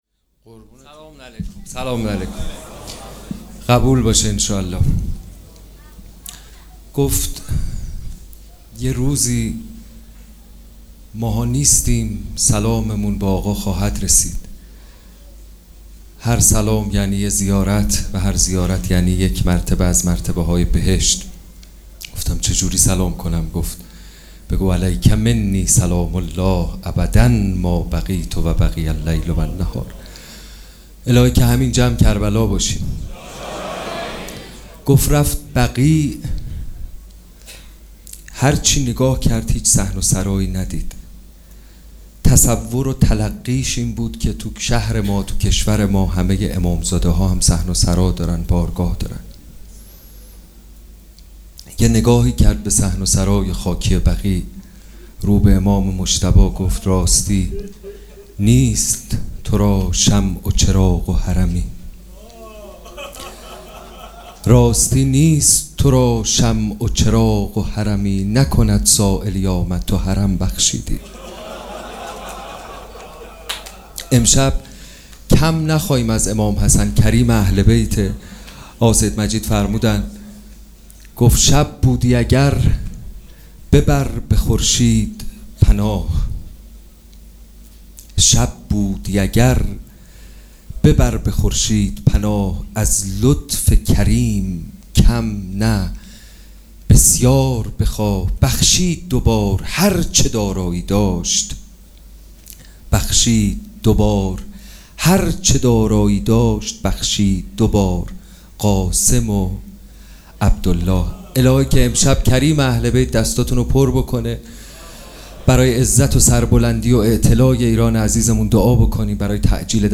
صحبت
مراسم عزاداری شب پنجم محرم الحرام ۱۴۴۷